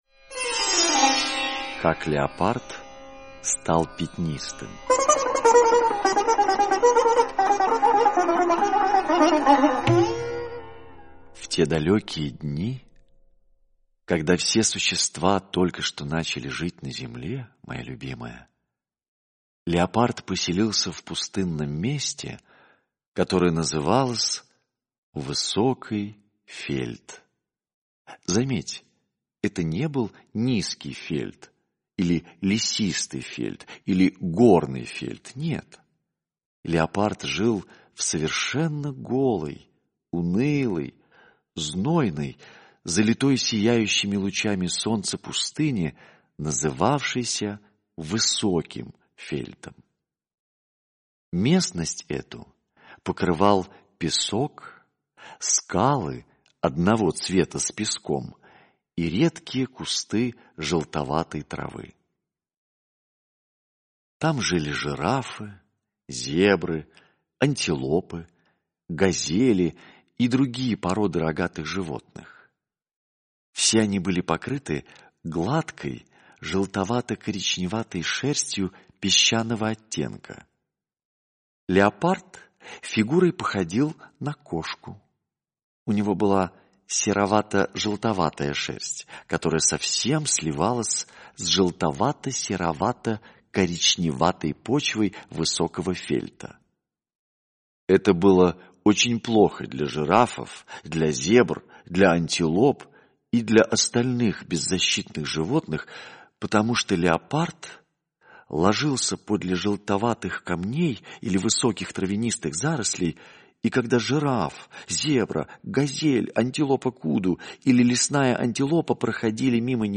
Как леопард стал пятнистым - аудиосказка Киплинга - слушать